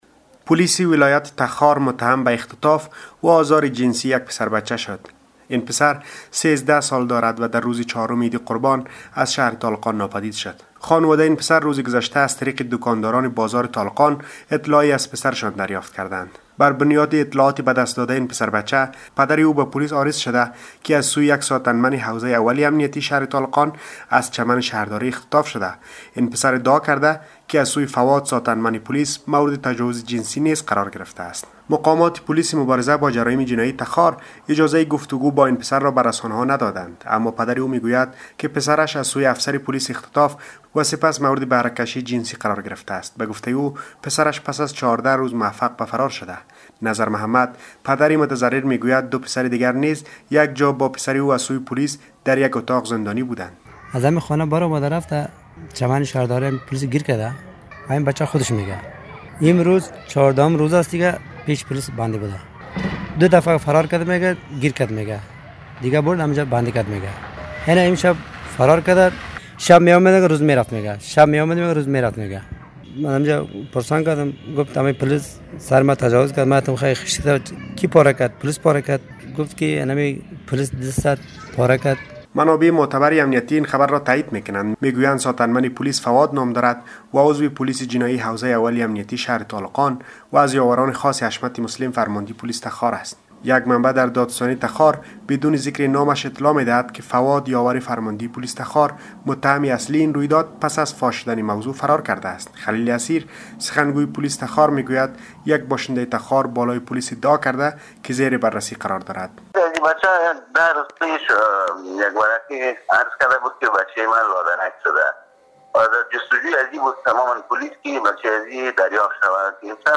مقامات پولیس تخار میگویند که فرد متهم فراری است و تلاش های پولیس برای دستگیری او جریان دارد. جزئیات بیشتر را در گزارش خبرنگارمان بشنوید